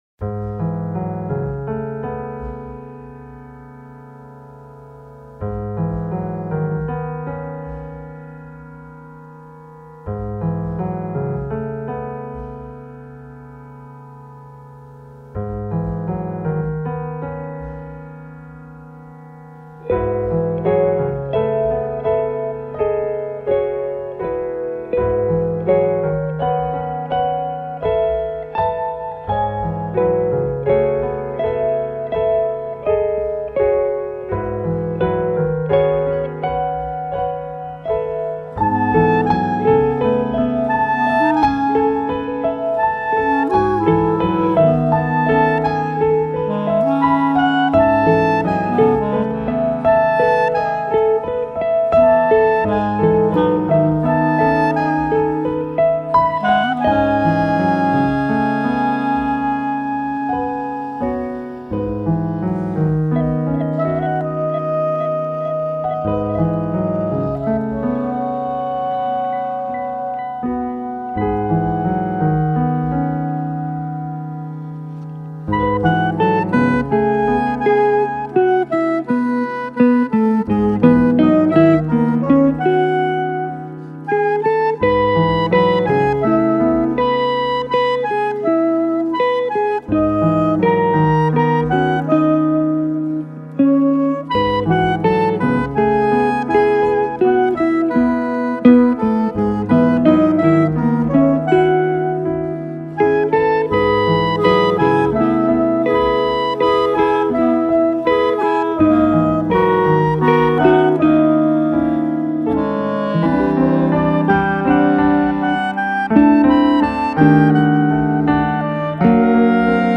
Categoría jazz fusión